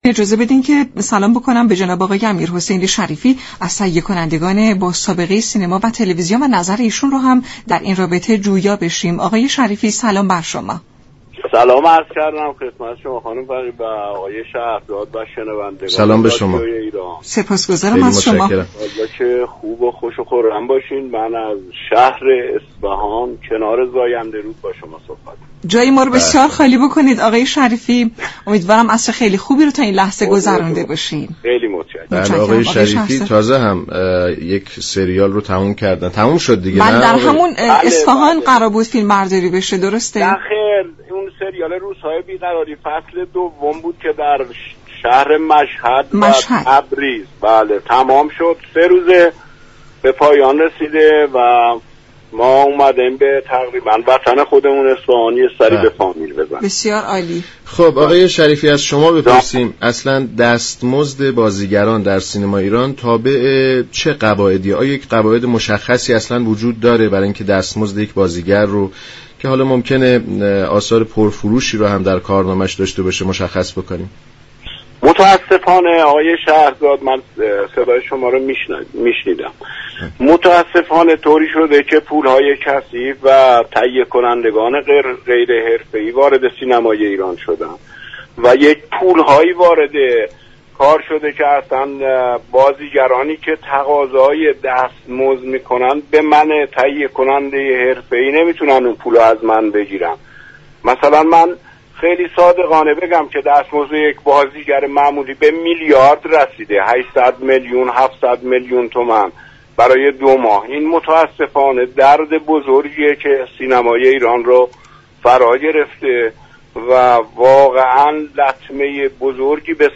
در گفت و گو با رادیو ایران گفت.